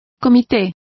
Complete with pronunciation of the translation of committee.